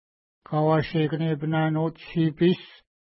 Pronunciation: ka:wa:ʃəkənepəna:nu:t-ʃi:pi:s